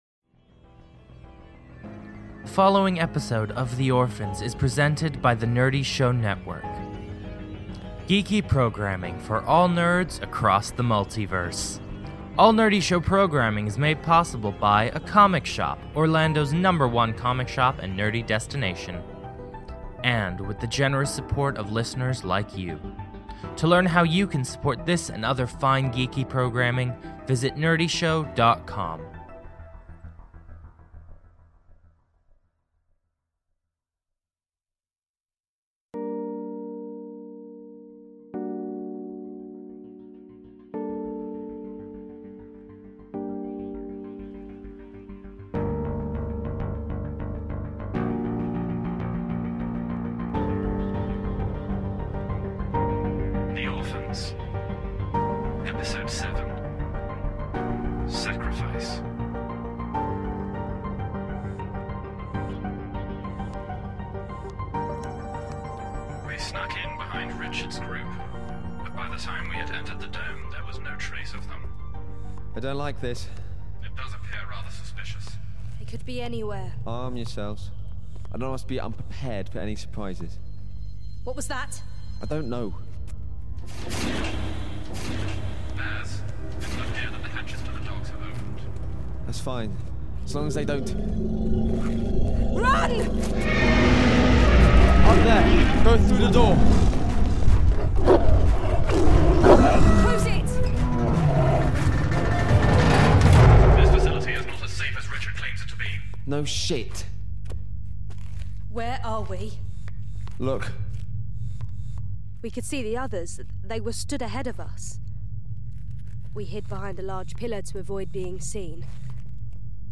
A tale of survival on an unknown world. The Orphans is a cinematic audio drama chronicling the castaways of downed starship, The Venture - Stranded on a hostile planet, struggling to remember how they arrived and who they are.